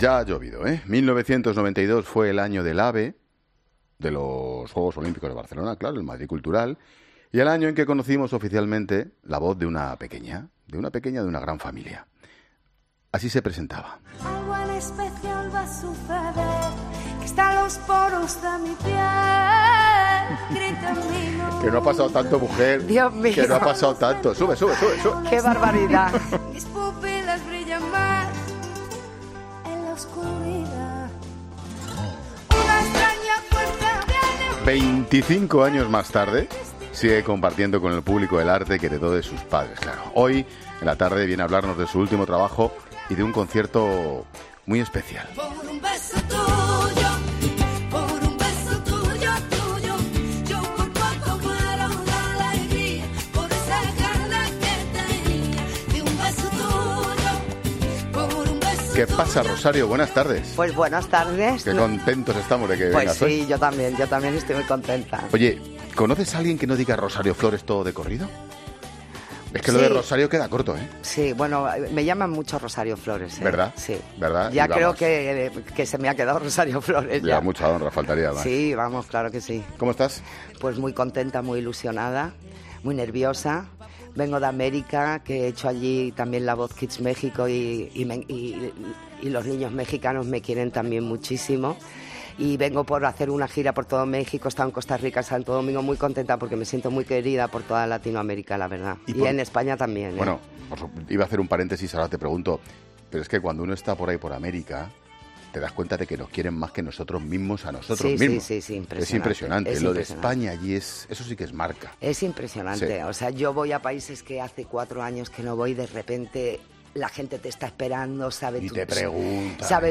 ESCUCHA LA ENTREVISTA COMPLETA | Rosario Flores en 'La Tarde'